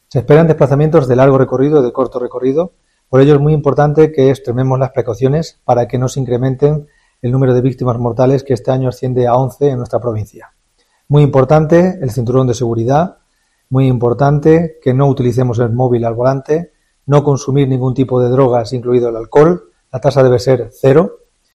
Subdelegado del Gobierno en Ávila sobre la Operación Salida